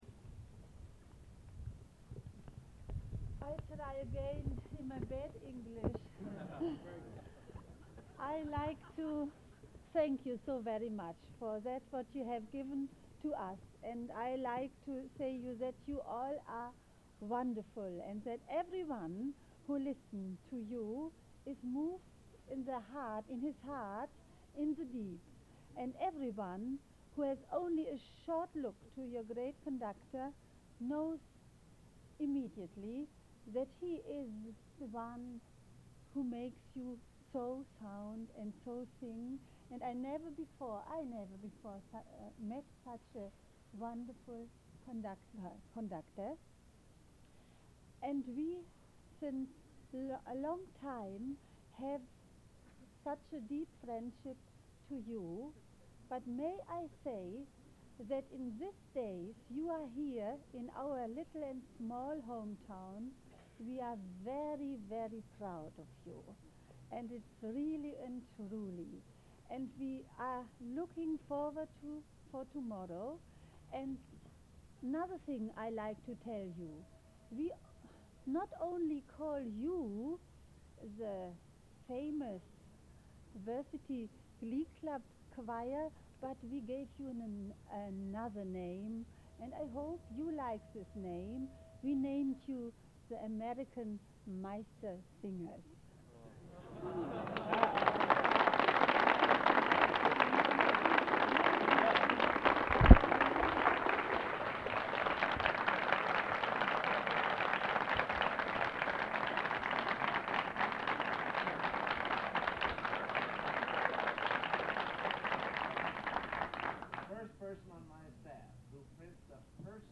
Collection: Bückeburg Garden Party
Genre: | Type: Director intros, emceeing